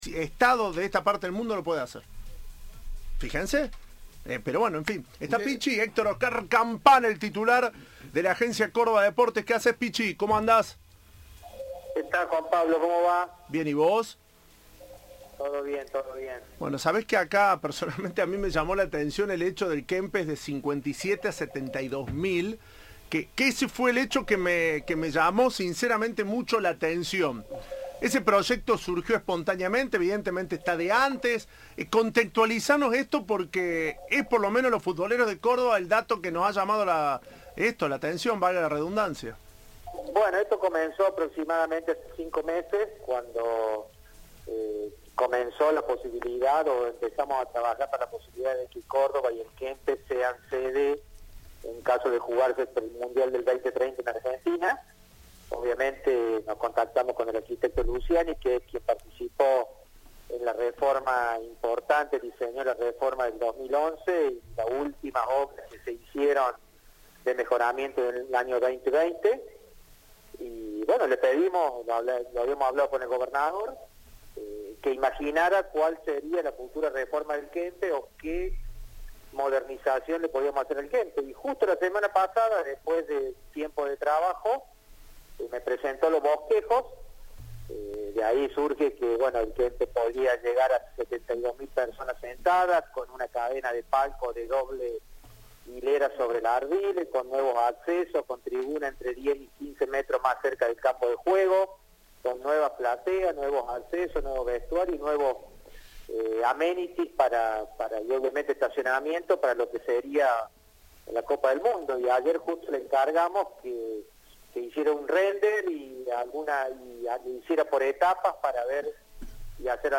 El Pichi Campana, presidente de la Agencia Córdoba Deportes, habló con Cadena 3 y se refirió a la ampliación del estadio y a la designación de Argentina como sede inaugural del Mundial 2030.